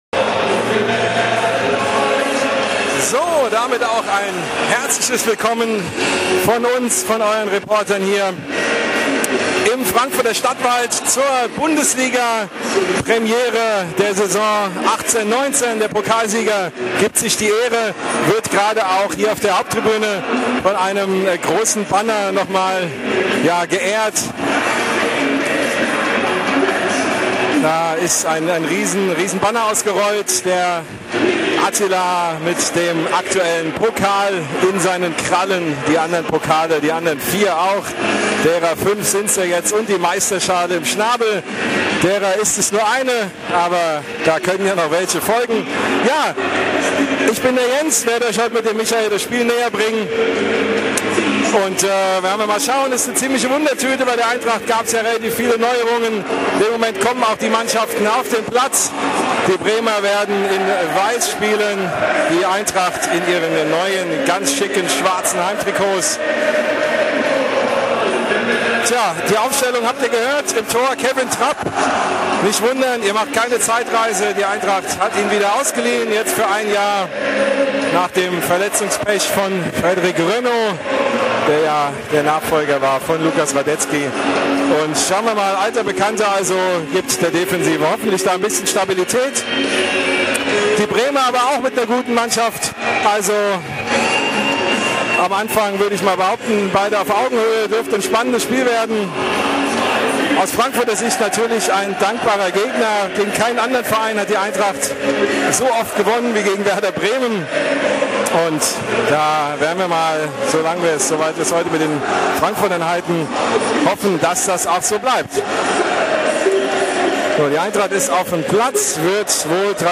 Die Spiel-Reportage im Player